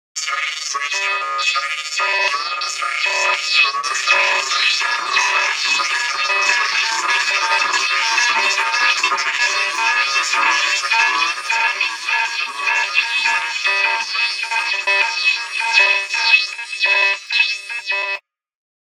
lovely sounds made with SFinst...